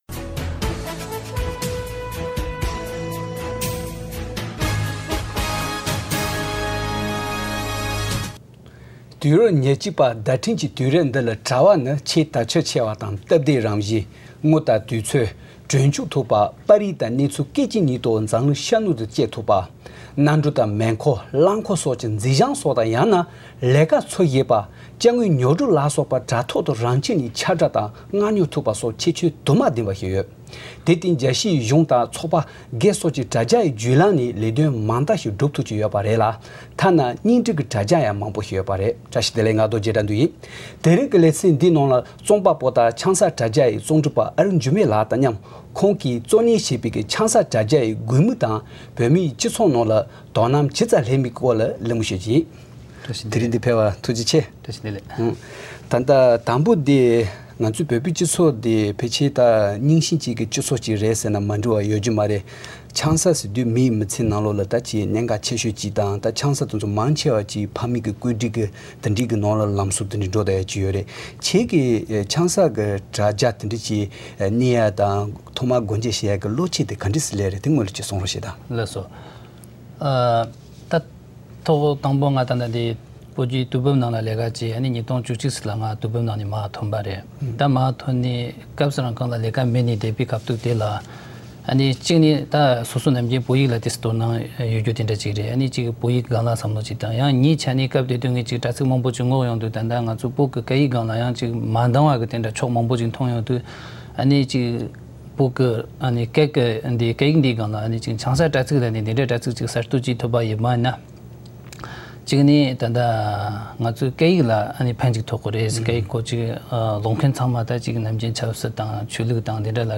གླེང་མོལ་བྱེད་པ།